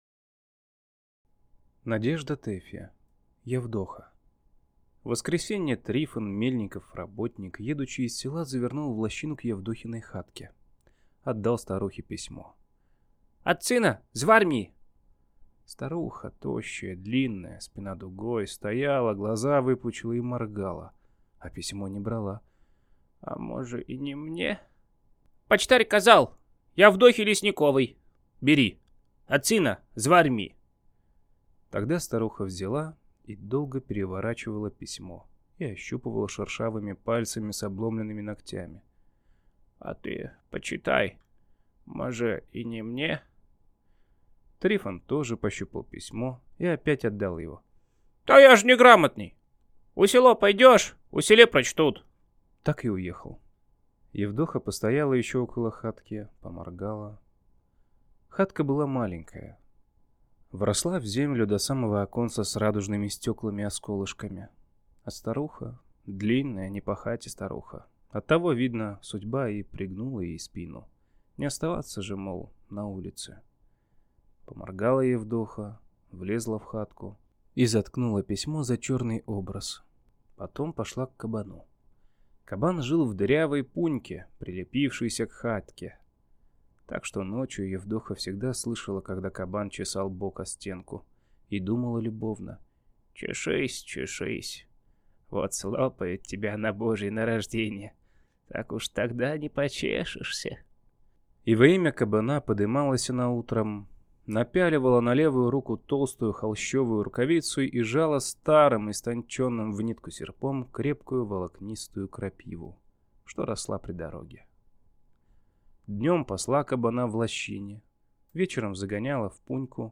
Аудиокнига Явдоха | Библиотека аудиокниг
Прослушать и бесплатно скачать фрагмент аудиокниги